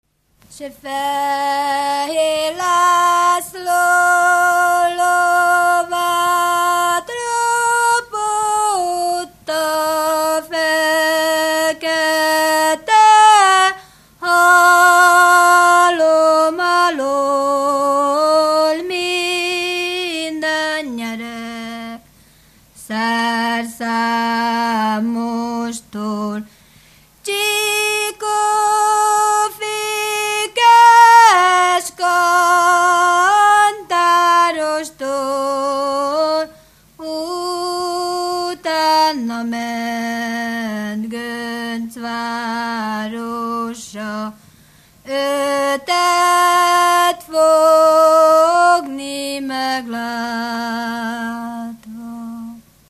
Erdély - Kolozs vm. - Méra
Műfaj: Ballada
Stílus: 3. Pszalmodizáló stílusú dallamok